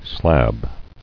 [slab]